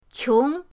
qiong2.mp3